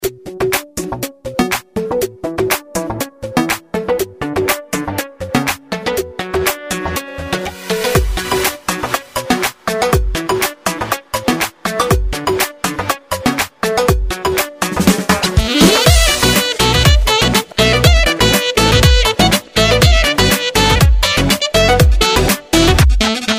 زنگ خور بی کلام موبایل (ریتمیک و ورزشی)